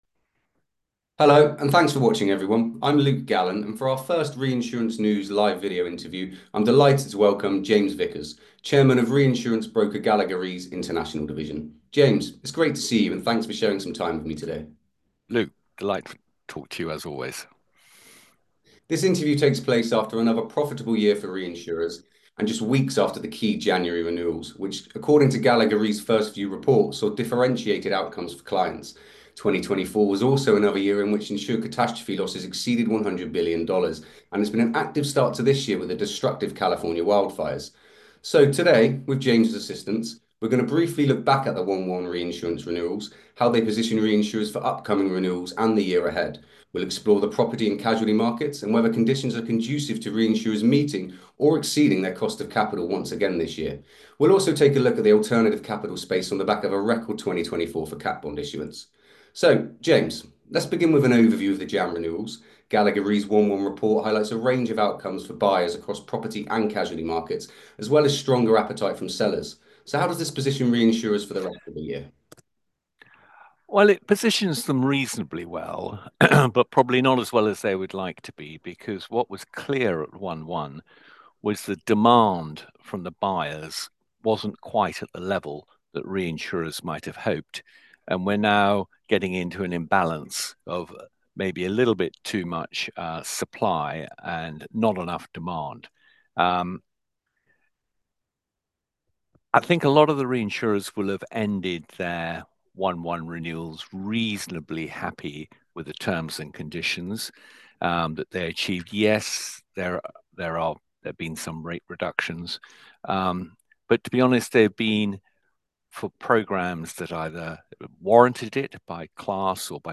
In a recent interview with our sister publication Reinsurance News